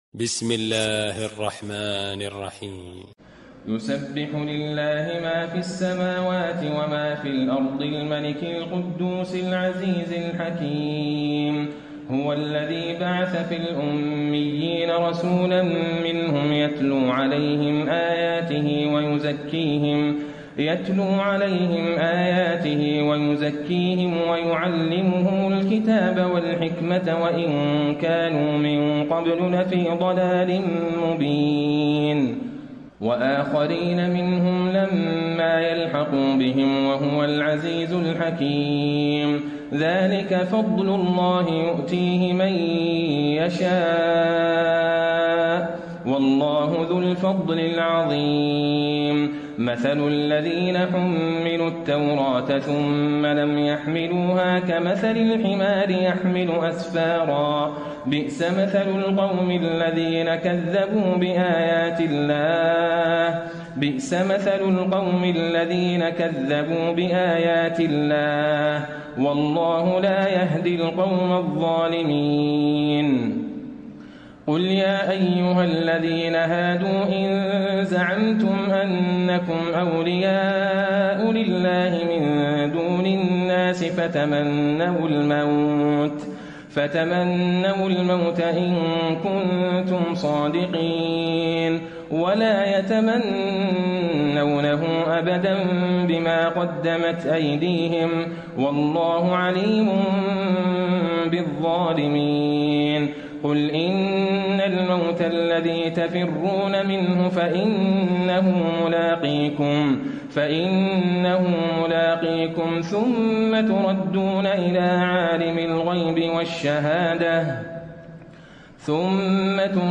تراويح ليلة 27 رمضان 1434هـ من سورة الجمعة الى التحريم Taraweeh 27 st night Ramadan 1434H from Surah Al-Jumu'a to At-Tahrim > تراويح الحرم النبوي عام 1434 🕌 > التراويح - تلاوات الحرمين